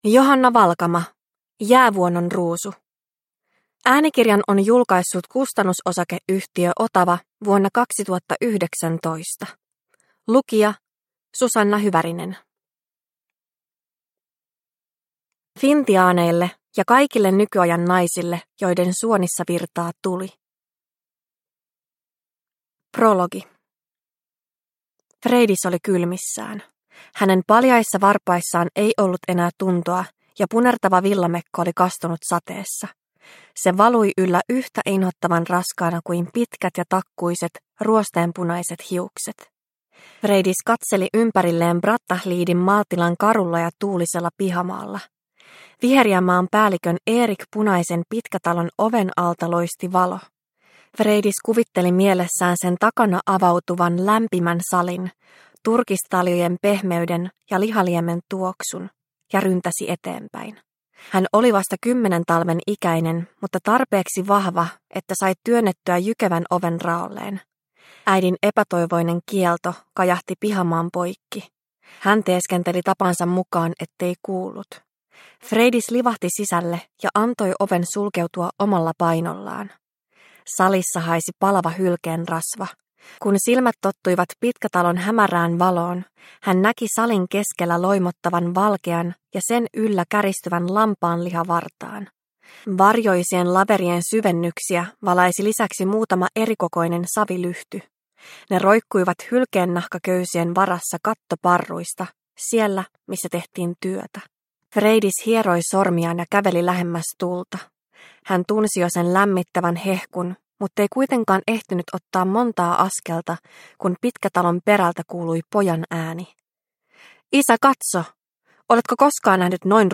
Jäävuonon Ruusu – Ljudbok – Laddas ner